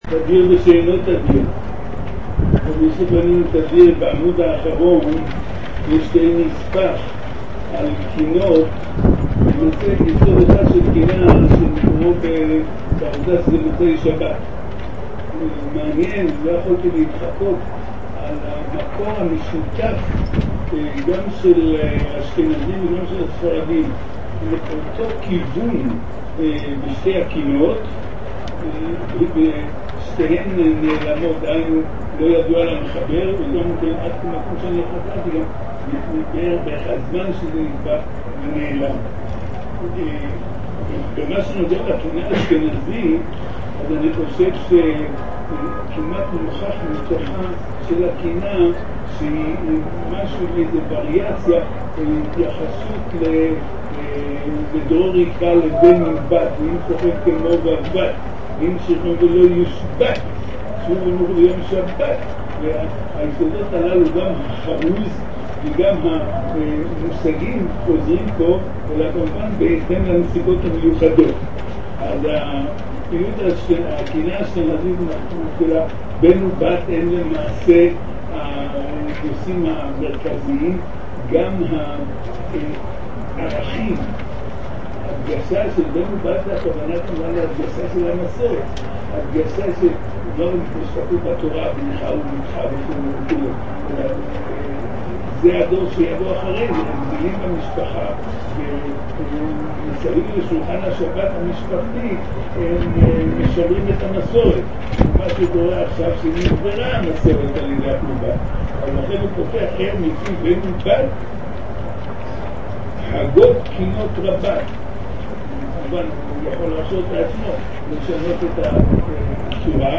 תשע"ה להאזנה לשיעור: https